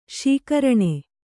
♪ śikaraṇe